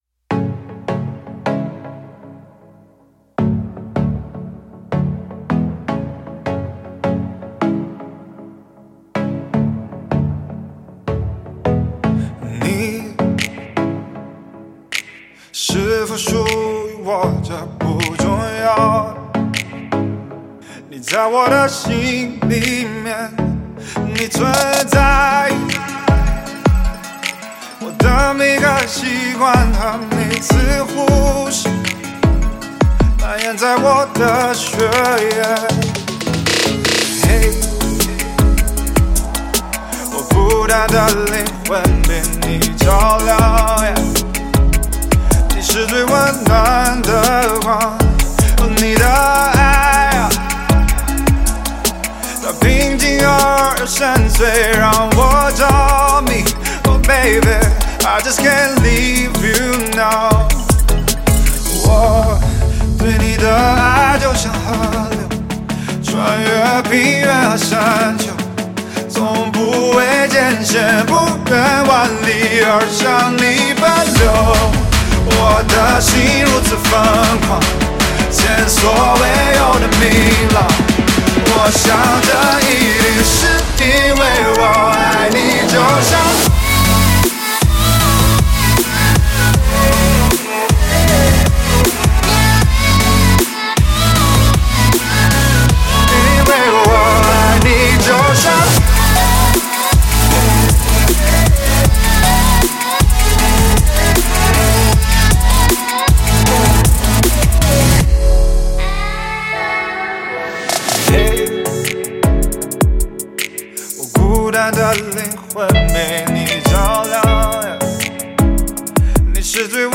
吉他
Ps：在线试听为压缩音质节选，体验无损音质请下载完整版